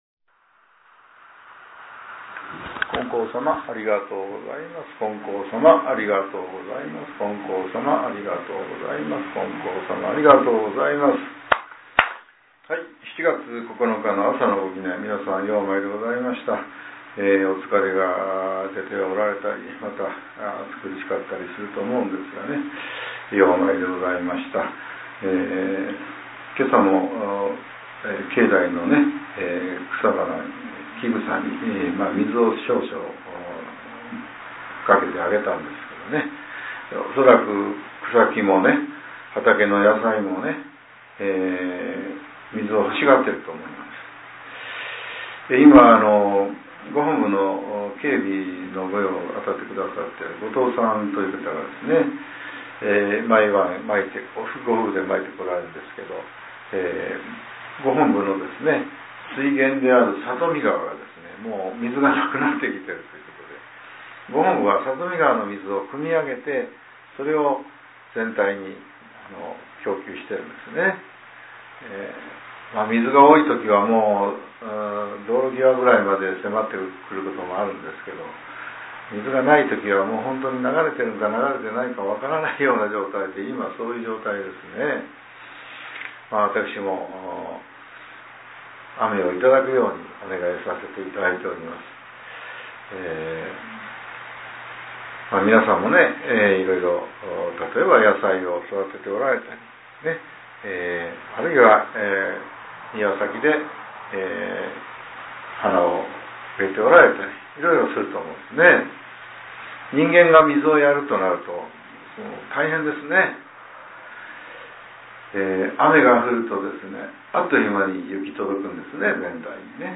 令和７年７月９日（朝）のお話が、音声ブログとして更新させれています。